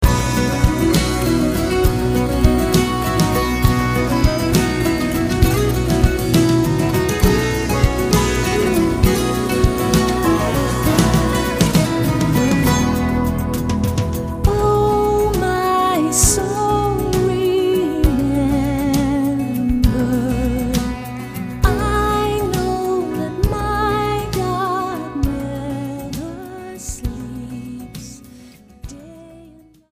STYLE: Roots/Acoustic
the use of sitar to give an Indian flavour